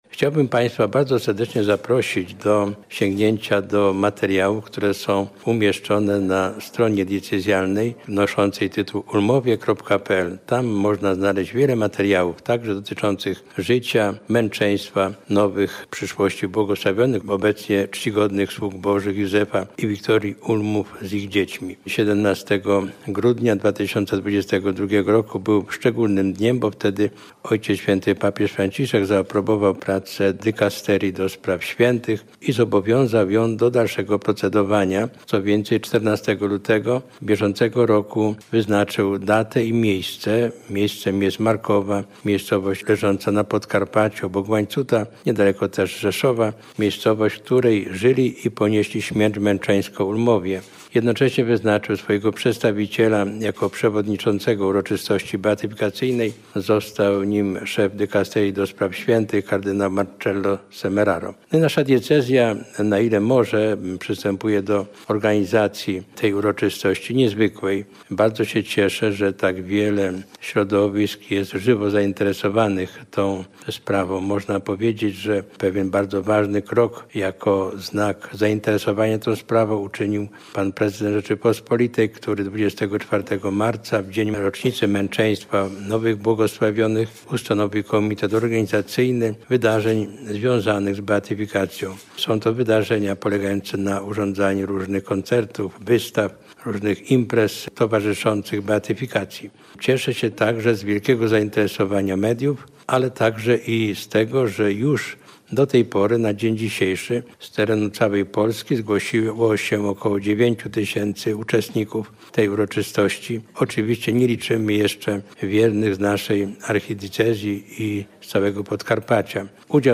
O życiorysie i przygotowaniach do uroczystości mówi ks. Abp Adam Szal z Archidiecezji Przemyskiej.